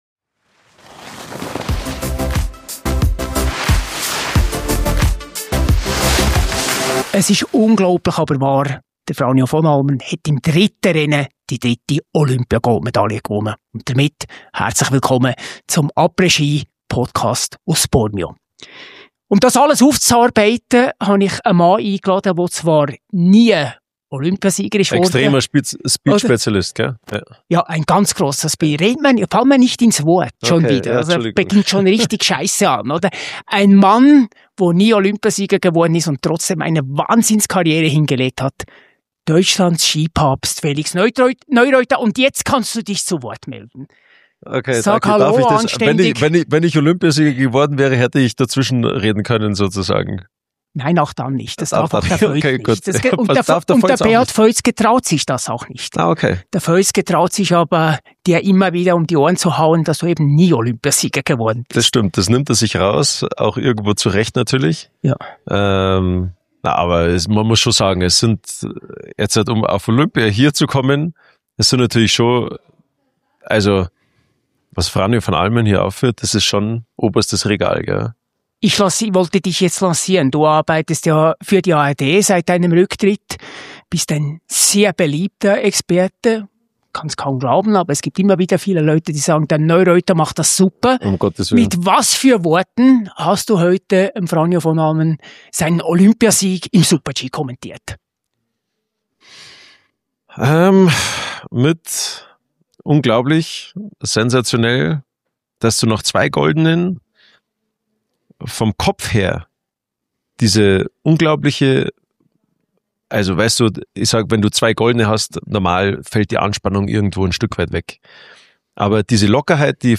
Après-Ski #26 direkt aus Bormio – Neureuther rechnet mit den Vonn-Kritikern ab ~ APRÈS-SKI - der Skipodcast der Schweiz Podcast